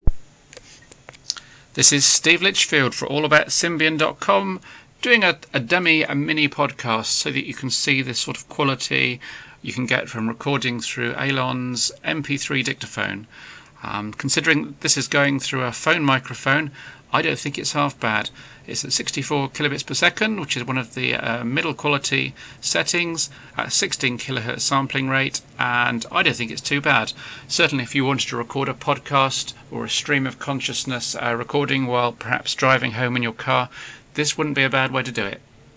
sample, at my chosen setting of 64kbps, 16KHz sampling rate, 16-bit audio (about half the overall 'quality' setting often used for MP3 music), it's not that bad, considering it's being recorded through a low grade smartphone mike.